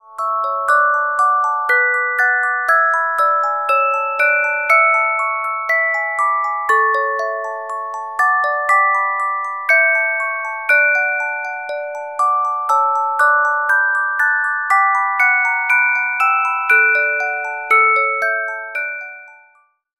Music Box Melodies柔美音樂盒